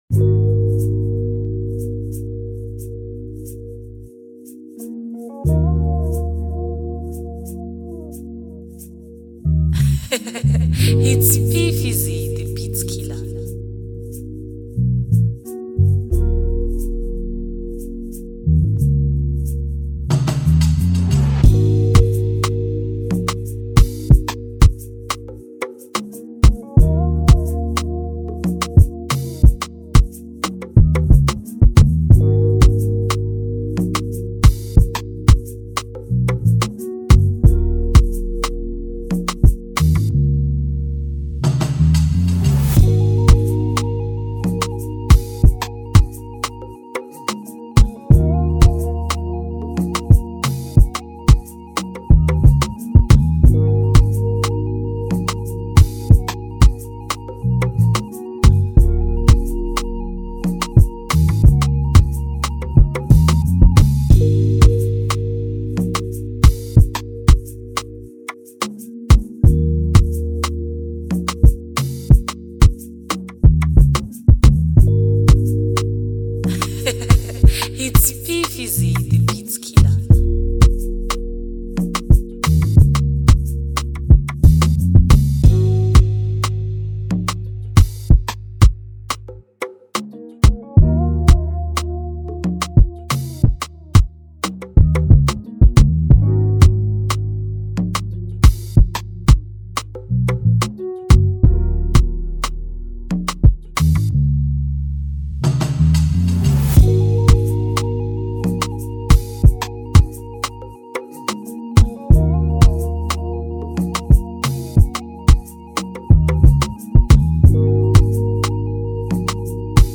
and it’s a reminisce of Nigerian type vibe.